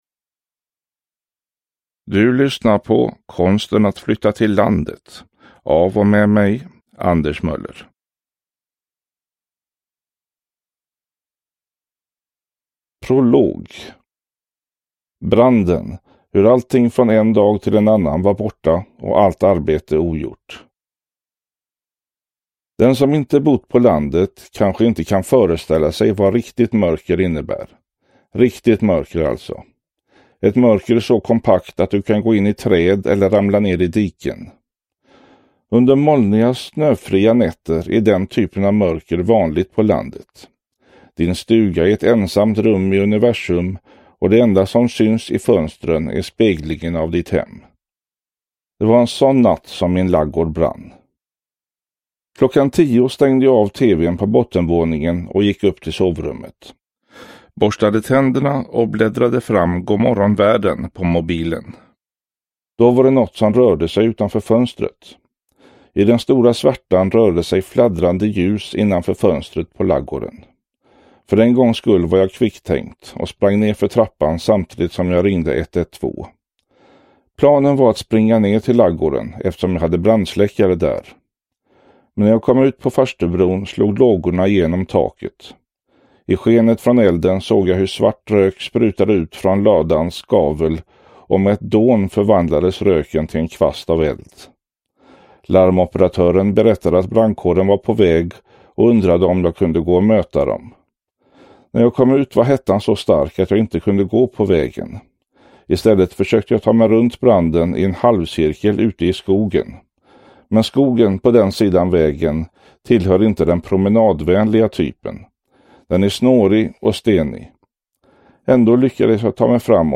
Konsten att flytta till landet – Ljudbok – Laddas ner